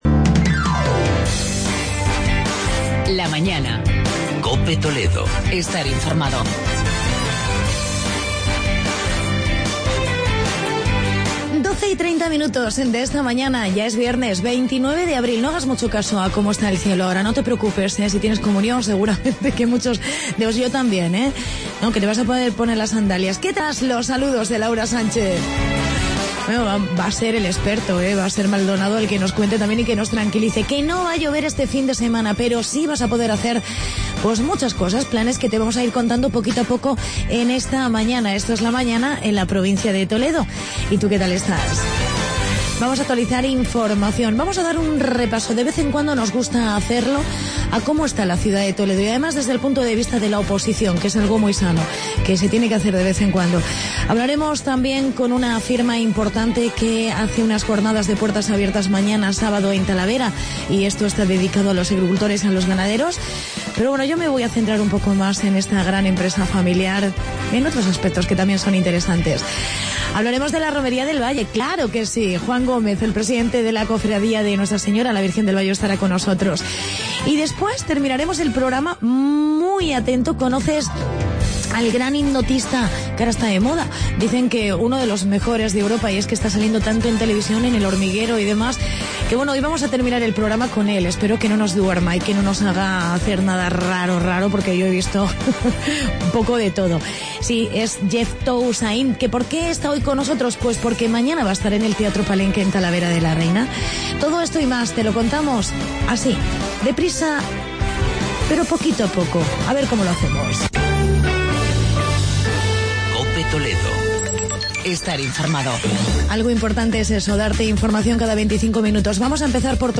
Entrevista a Jesús Labrador